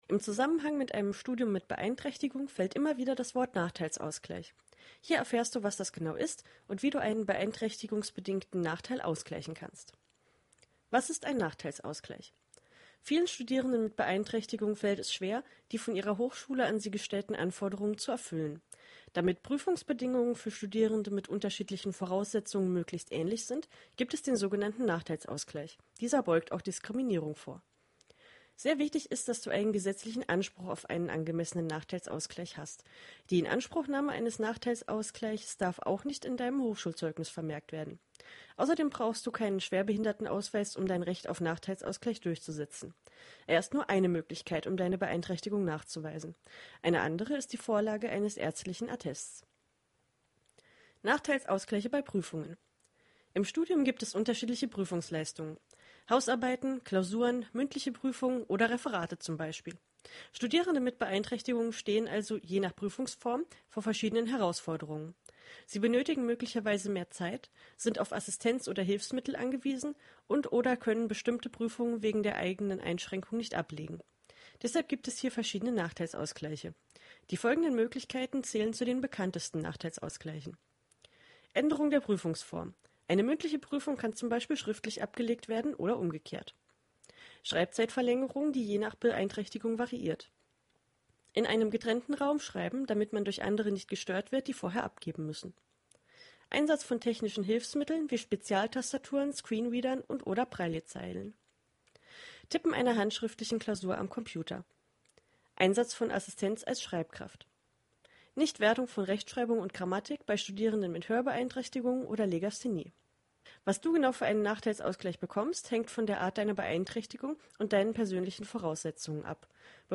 Wir haben ihn auch so für dich eingesprochen:
Vertonung_Nachteilsausgleich.mp3